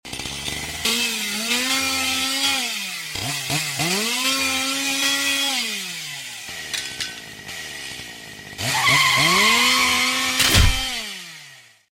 Top motorcycle driver sound effects free download